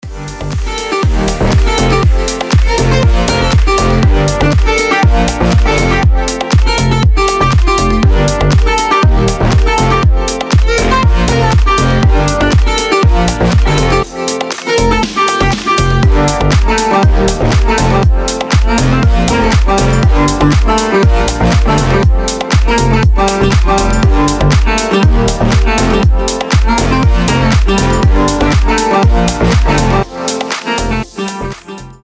• Качество: 320, Stereo
гитара
deep house
мелодичные
без слов
восточные
Стиль: deep house